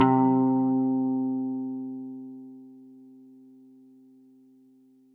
Pigs Guitar.wav